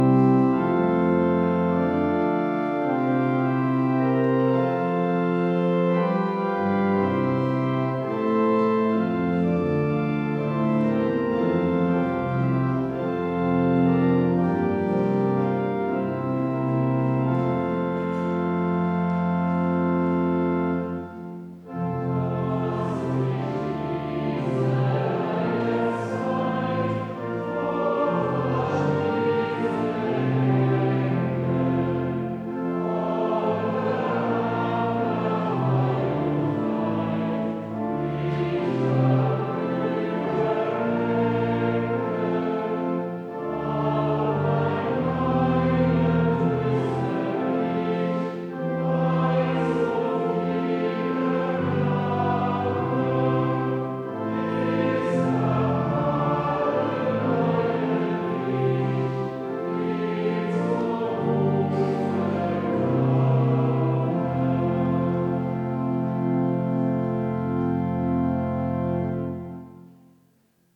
Audiomitschnitt unseres Gottesdienstes am Palmsonntag 2025.